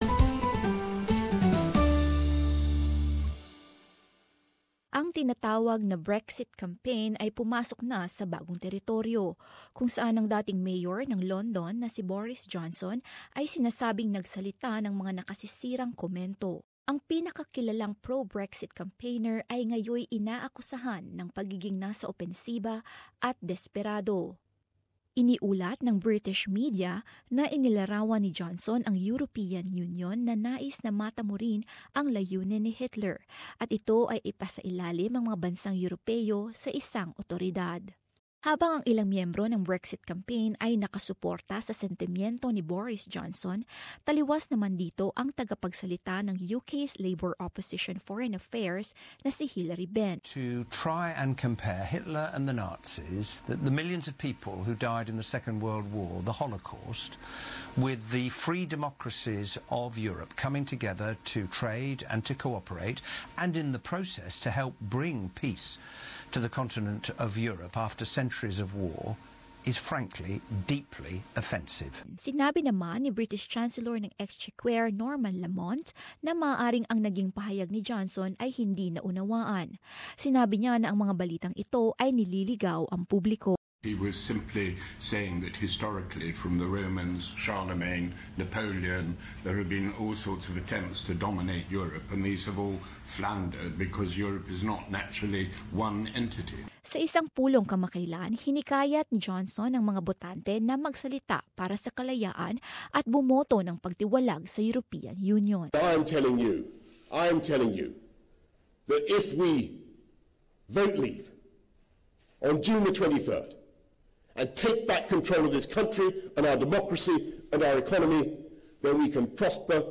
But as this report shows, it's not before politicians use the time to campaign for and against staying in the European Union.